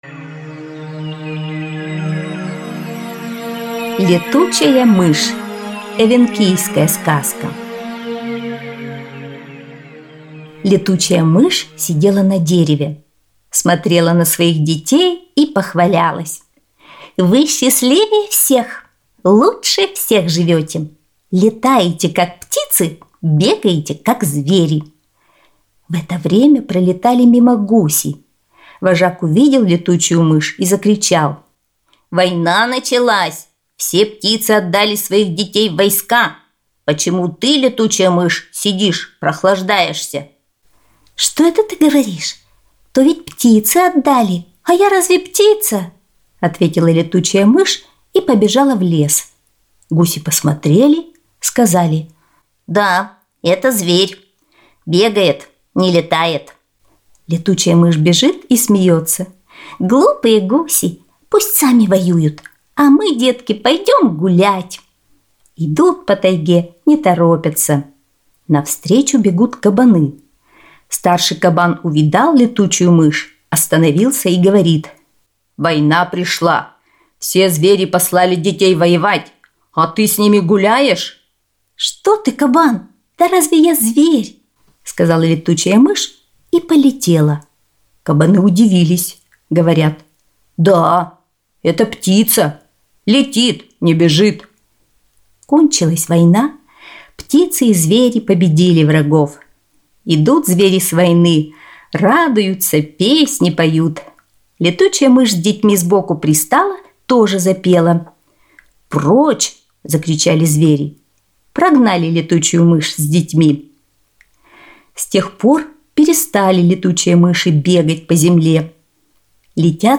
Эвенкийская аудиосказка